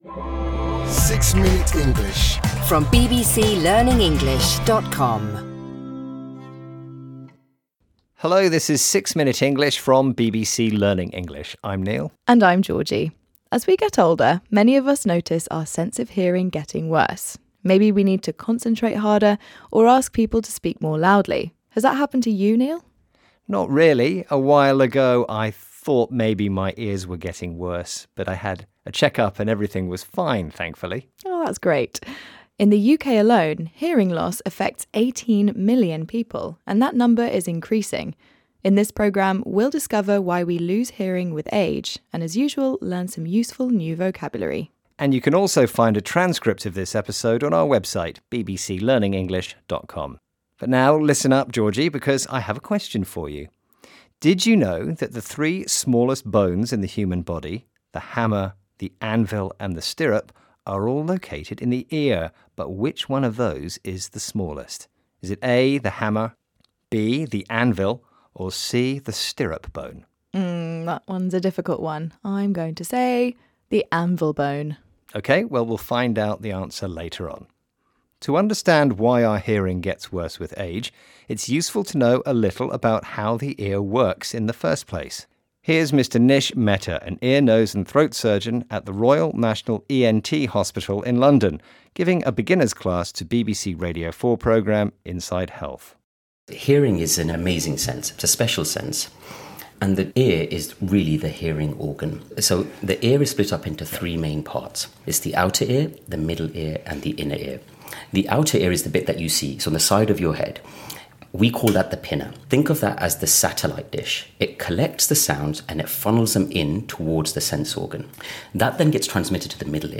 گویندگان این پادکست با لهجه انگلیسی بریتانیایی (British English) صحبت می‌کنند که منبعی ایده‌آل برای افرادی است که قصد شرکت در آزمون آیلتس دارند.
هر قسمت این پادکست شامل گفت‌وگویی کوتاه و جذاب درباره موضوعات متنوعی است که به زبان ساده و قابل‌فهم ارائه می‌شود تا به شنوندگان در تقویت مهارت‌های شنیداری، مکالمه و یادگیری واژگان جدید کمک کند.